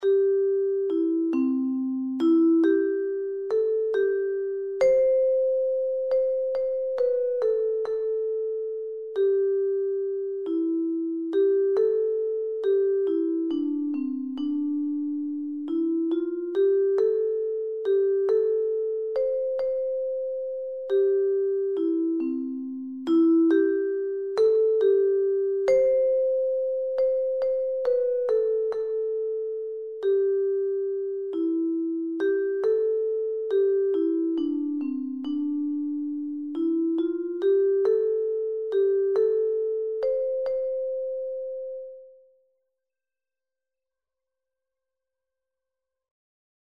Birthday Song
Rocking tempo